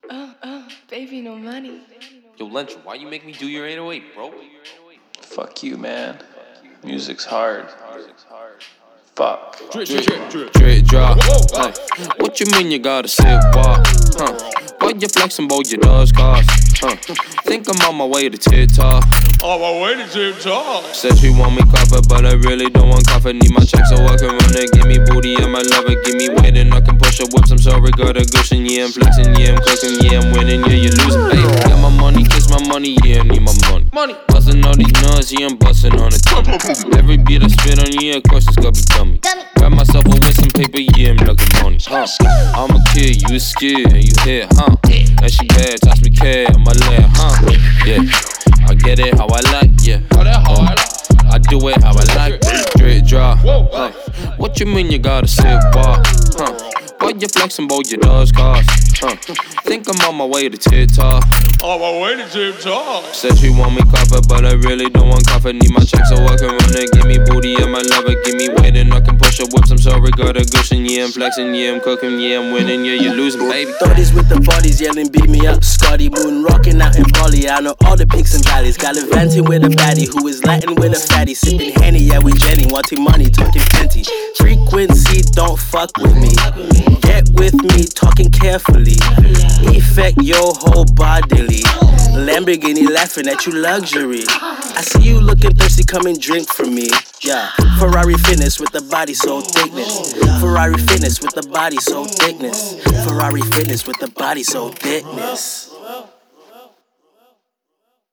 это энергичная трек в жанре хип-хоп